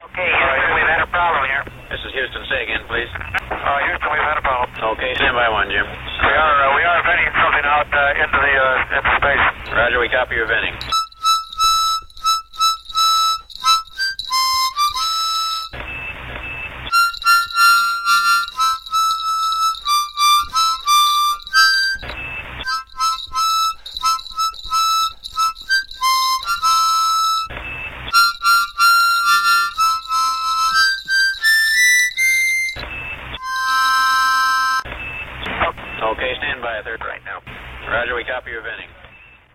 Then Wally played Jingle Bells on his tiny four-hole Little Lady harmonica while someone, perhaps his co-pilot Tom Stafford, also shook some mini sleigh bells.